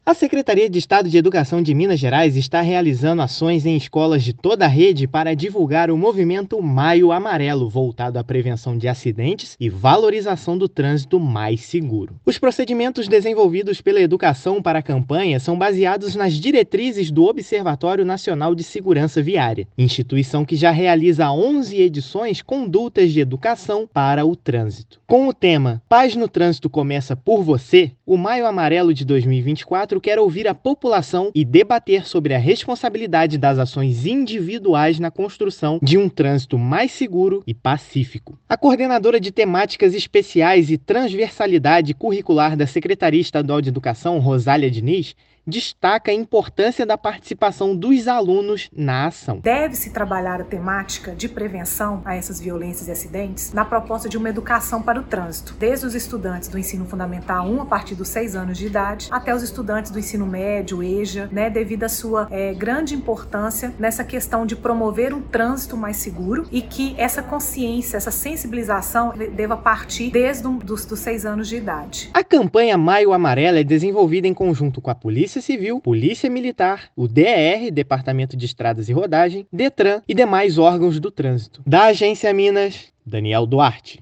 Ação realizada em conjunto com outros órgãos do Estado quer levar mais paz ao trânsito. Ouça matéria de rádio.